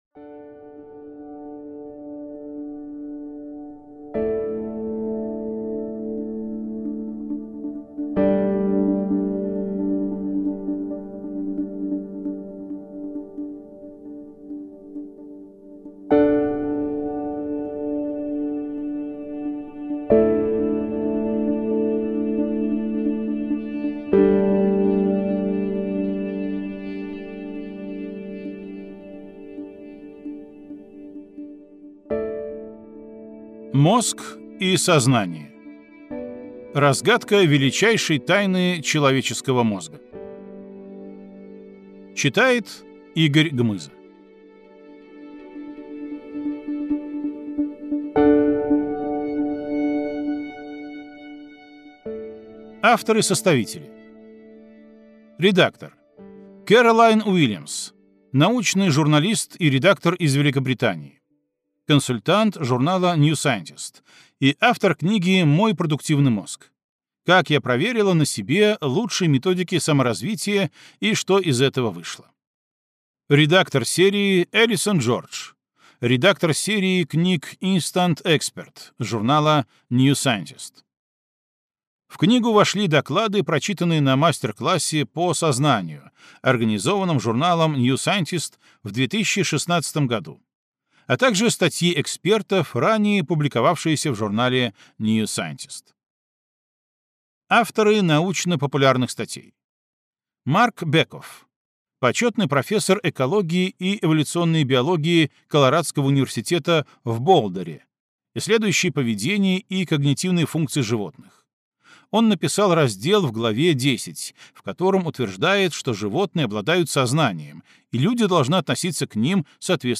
Аудиокнига Мозг и сознание. Разгадка величайшей тайны человеческого мозга | Библиотека аудиокниг